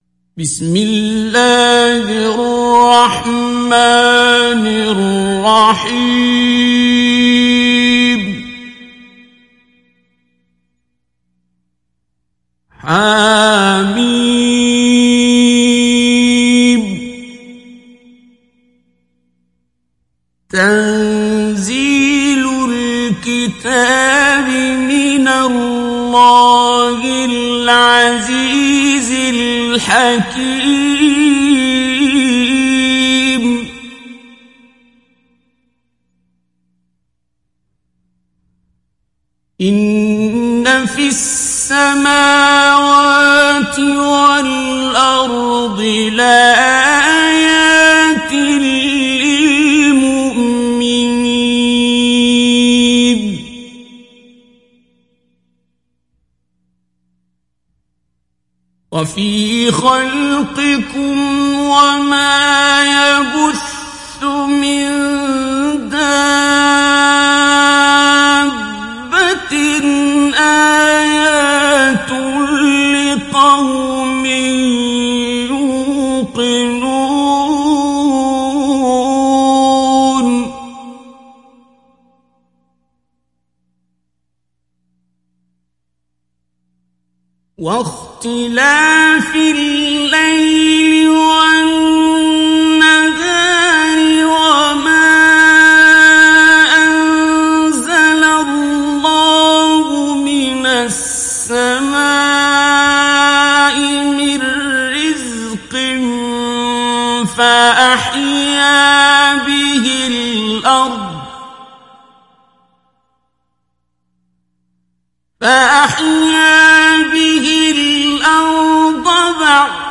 Mujawwad